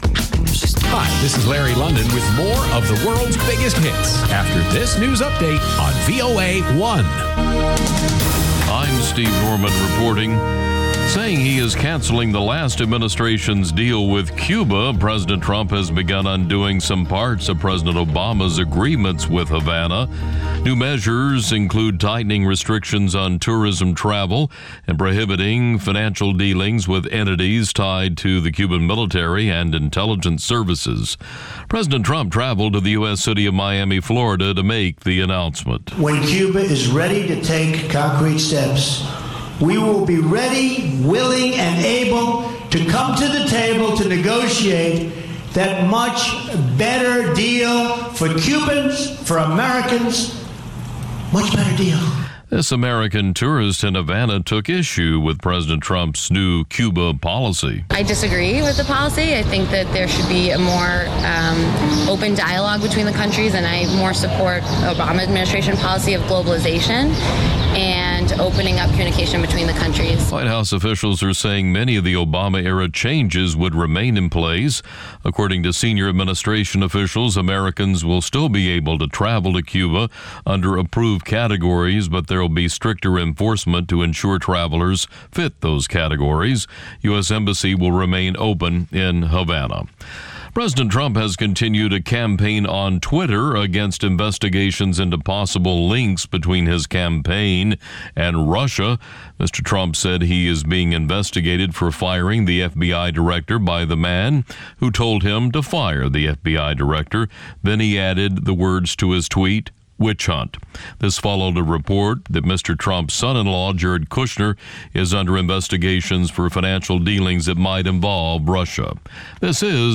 "ამერიკის ხმის" ახალი ამბები (ინგლისურად) + VOA Music Mix